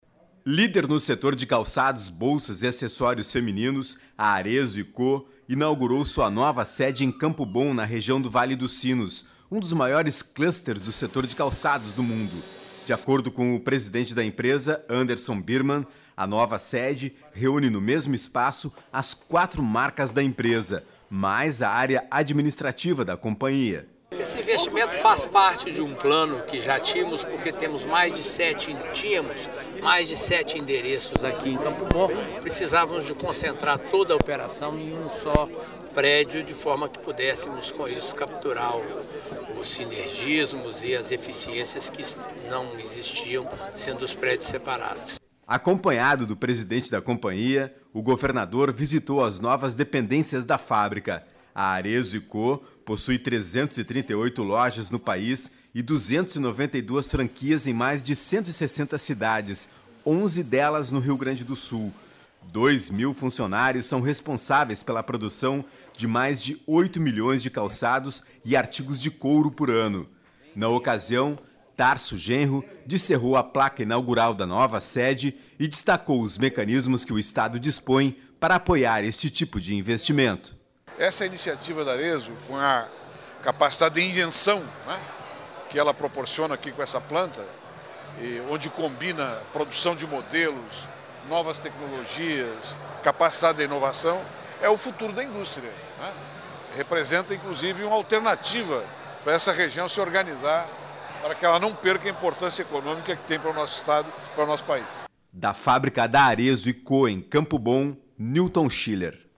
boletim-arezzo.mp3